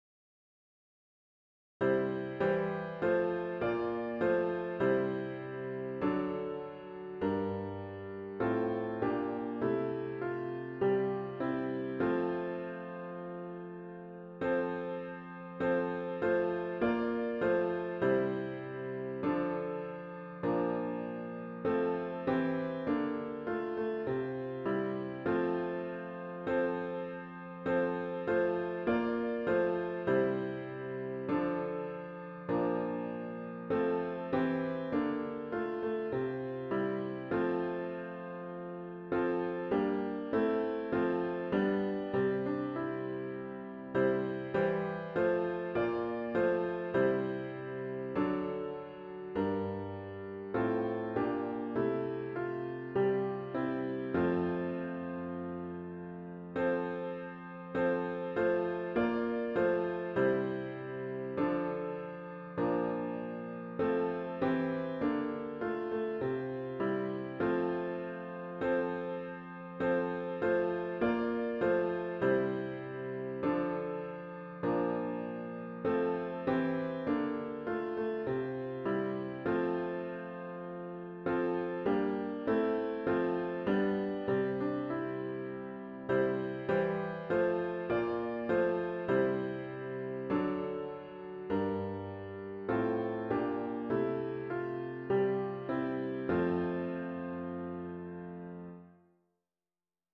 Sunday December 22, 2024 Worship Service